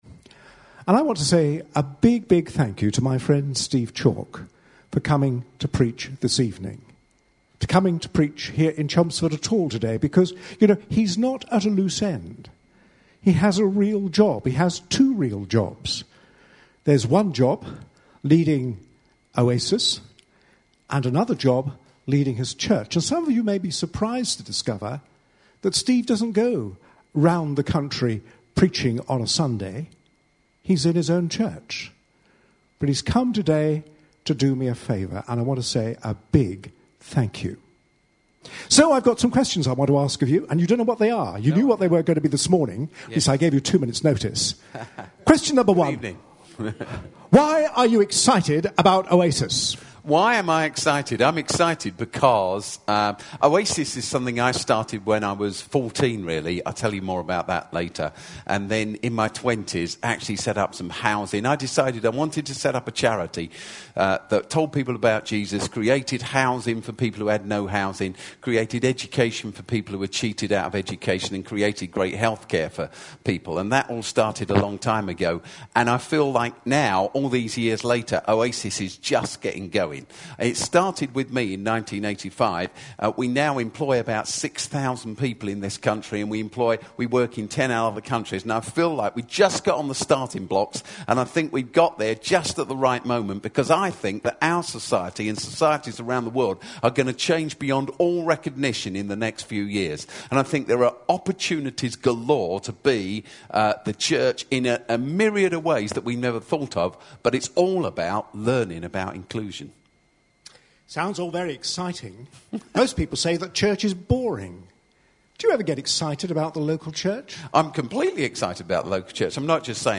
A sermon preached on 19th May, 2013, as part of our 108th Church Anniversary Services series.